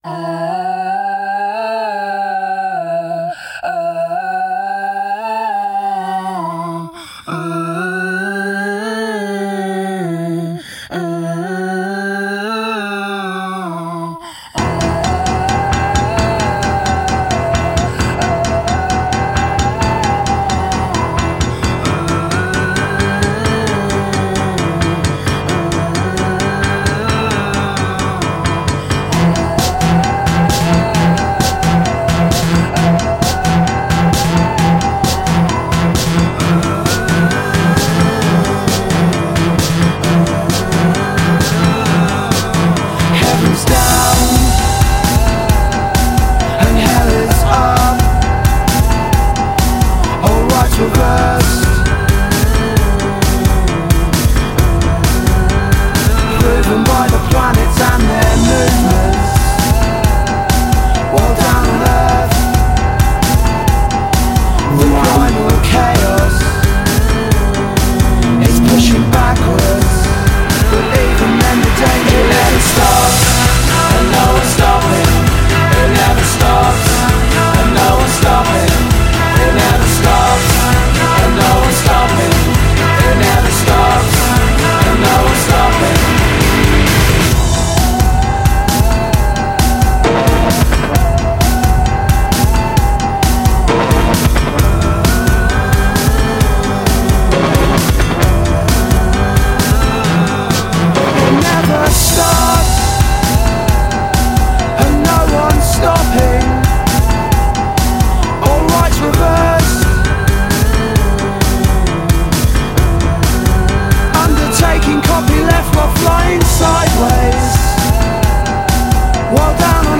Жанр:Electronic